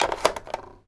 phone_pickup_handle_03.wav